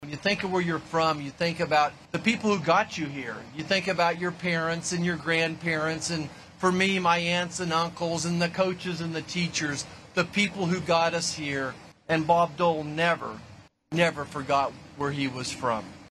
Speaking at a wreath laying ceremony at the World War II Memorial Wednesday, Sen. Roger Marshall spoke about Dole reminding folks to remember where they’re from.